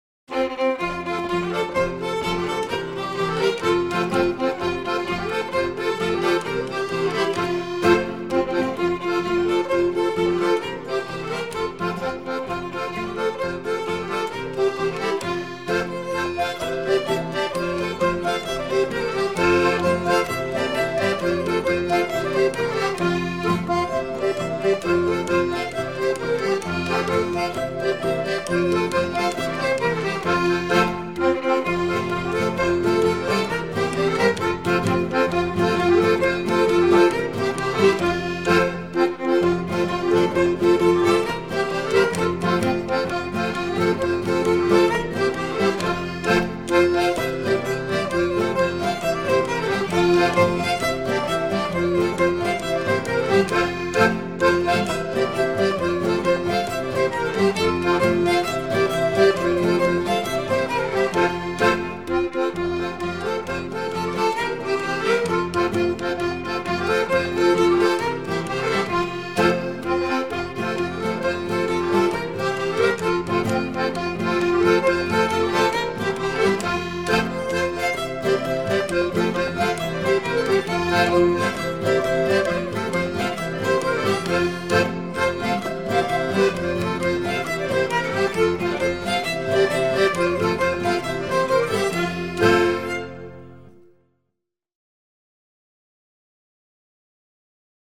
Ticino: Genuine Folk Music from Southern Switzerland